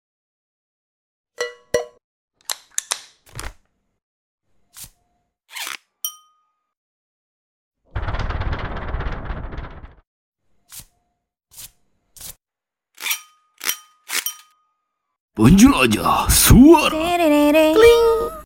Menjelajah suara dari film Disney sound effects free download